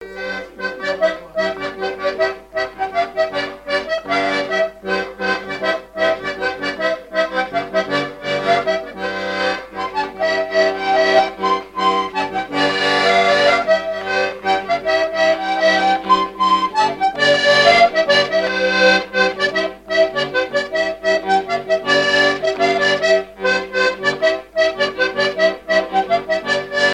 Mémoires et Patrimoines vivants - RaddO est une base de données d'archives iconographiques et sonores.
Chants brefs - A danser
danse : mazurka
danses à l'accordéon diatonique et chansons
Pièce musicale inédite